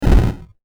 bomb.mp3